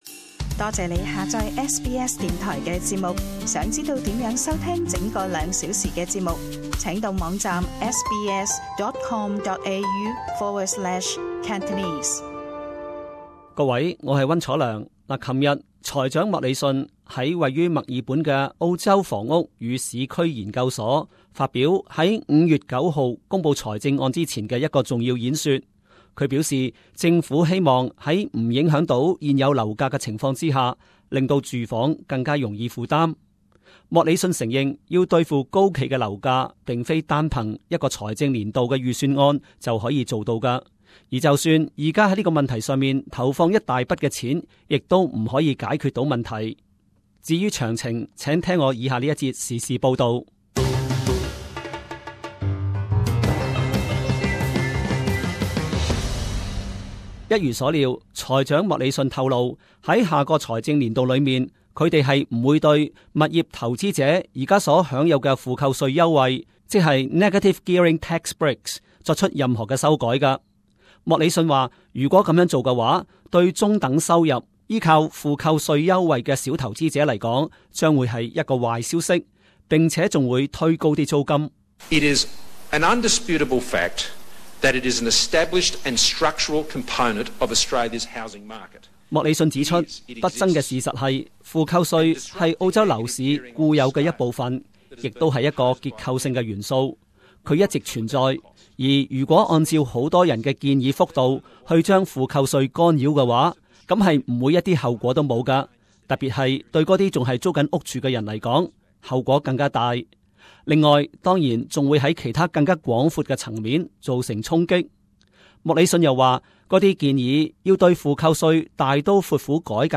【時事報導】 莫理遜否定對負扣稅作變更